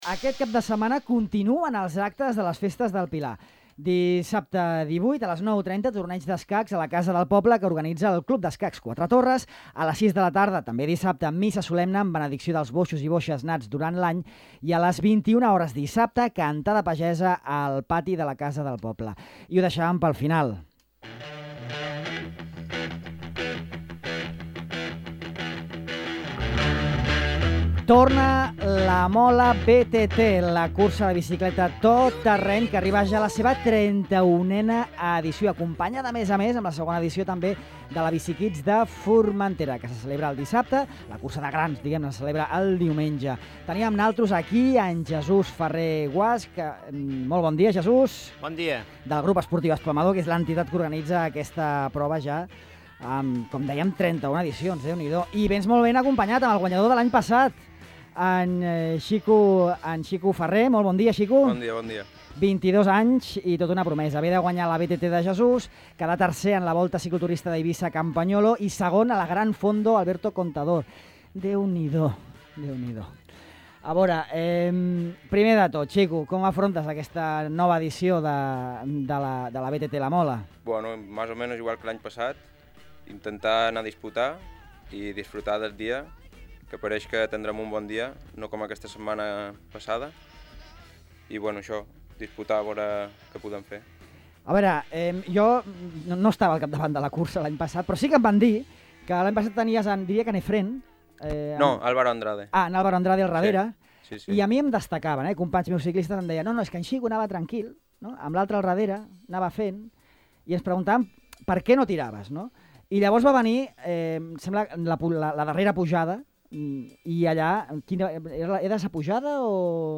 En la XXXI edició, la cursa repeteix un circuit similar al de l’any passat, amb un exigent recorregut d’uns 30 km, amb bardes especialment tècniques i més de 600 metres de desnivell acumulat. Podeu escoltar l’entrevista completa en aquest reproductor.